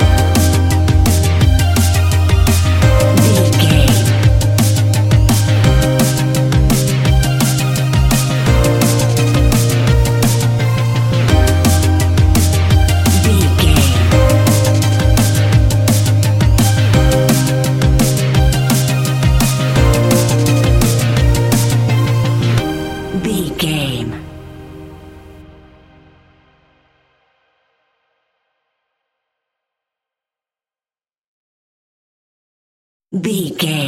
Ionian/Major
C♭
techno
trance
synthesizer
synthwave
instrumentals